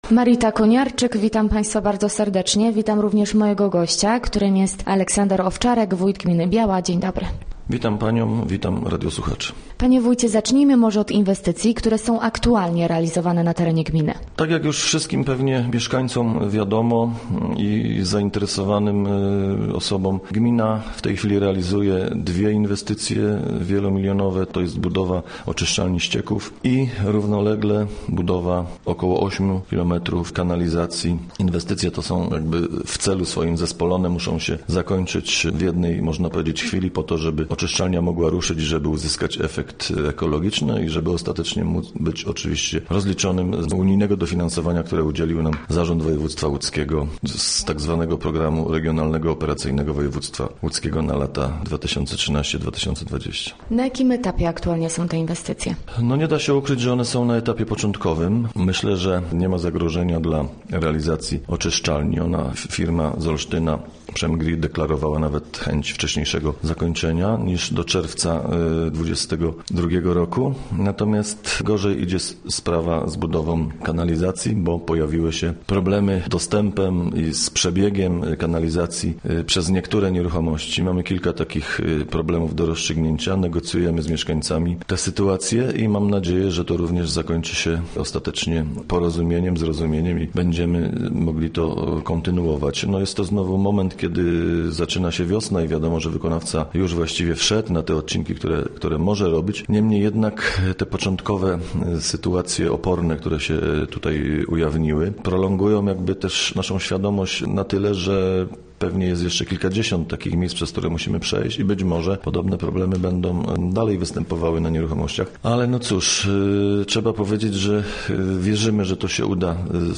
Gościem Radia ZW był Aleksander Owczarek, wójt gminy Biała